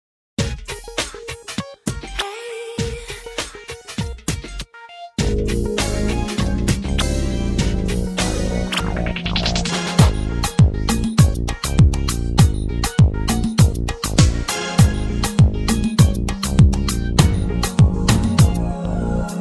Chant
Basse
Guitares
Claviers